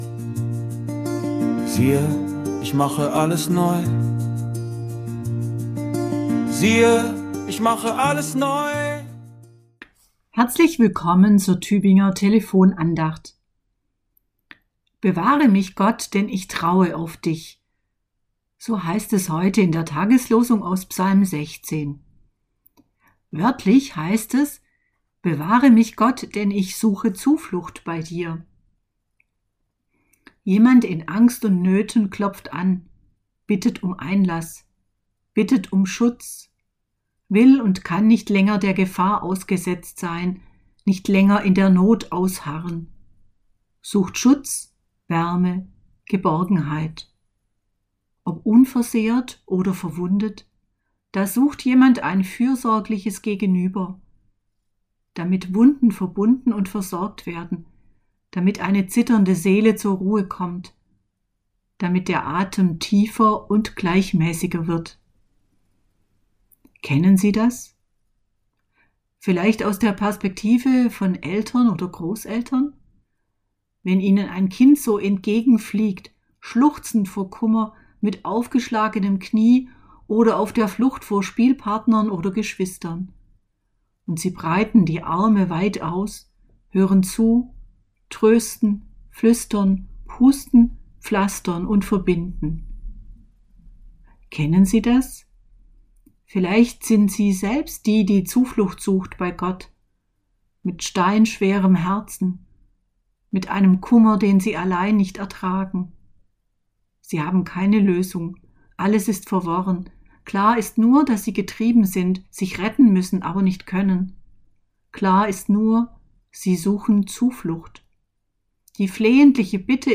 Andacht aus dem Januar